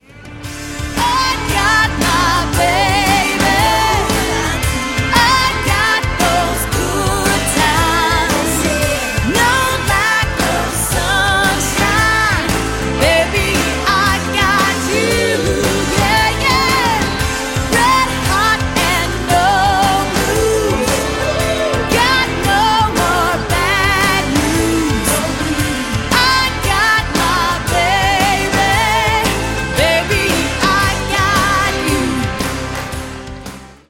90s Country Music